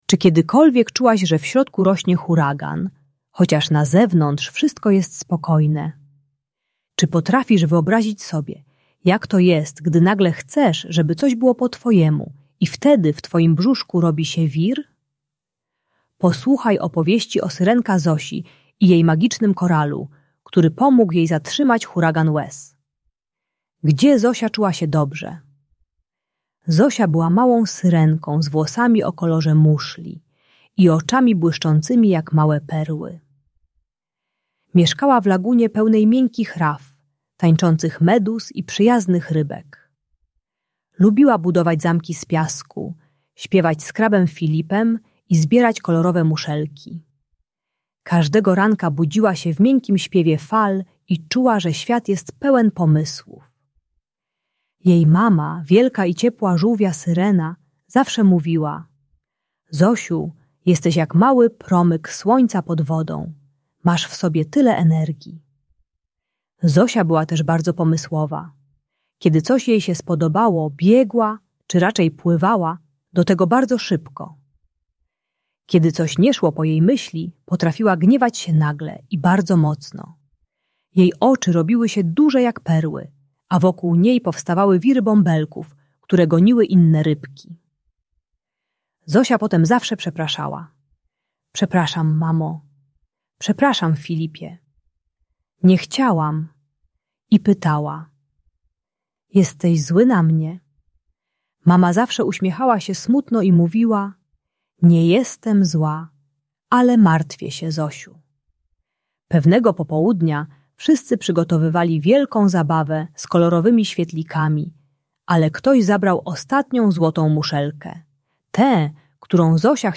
Syrenka Zosia i jej magiczny koral - Bunt i wybuchy złości | Audiobajka